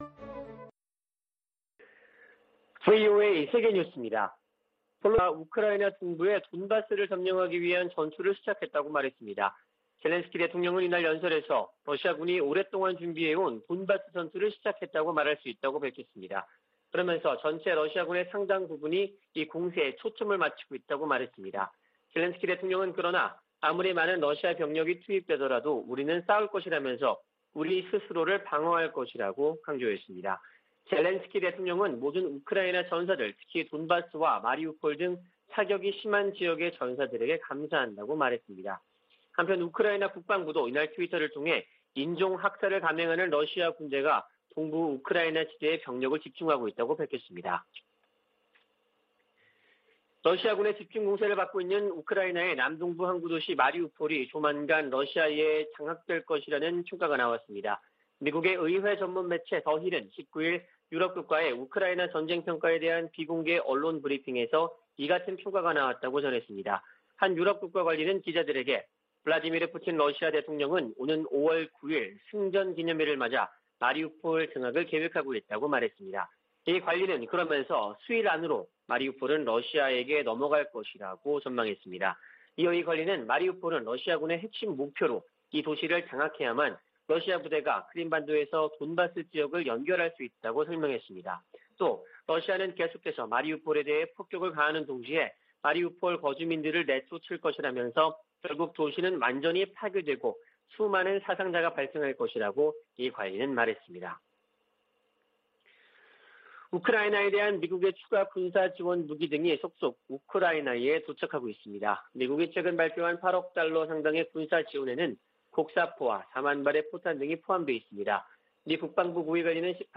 VOA 한국어 아침 뉴스 프로그램 '워싱턴 뉴스 광장' 2022년 4월 20일 방송입니다. 미 국무부는 북한이 도발을 계속하면 상응 조치를 이어갈 것이라고 경고했습니다.